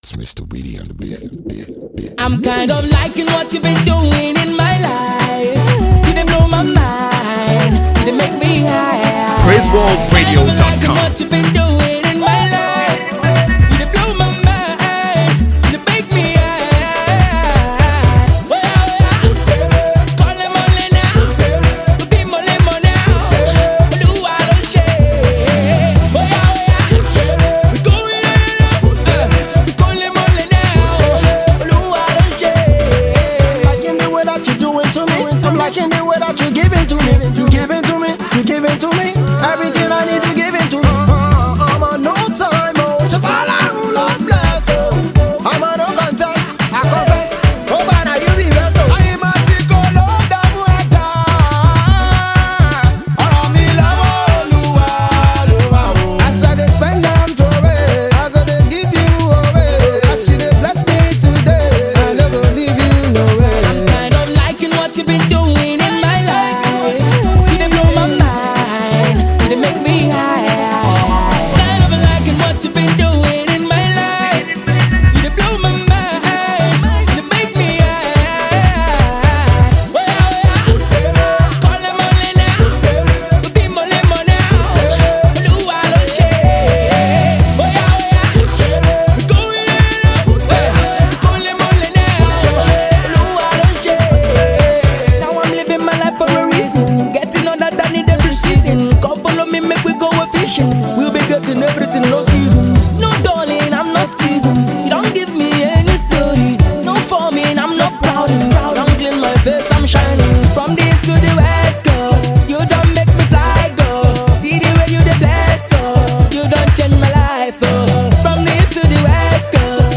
Nigerian gospel group
groovy single